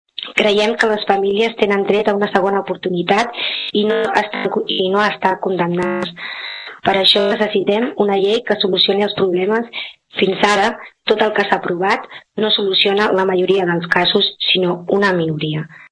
El Ple de l’Ajuntament de Tordera va aprovar per unanimitat en la sessió ordinària d’ahir una moció presentada a instàncies de la Plataforma d’Afectats per la Hipoteca de Tordera pronunciant-se a favor de mesures urgents per combatre els desnonaments i la pobresa energètica.
La socialista Toñi Garcia va recordar que les situacions que s’ esmenten en la iniciativa legislativa popular també es troben a Tordera.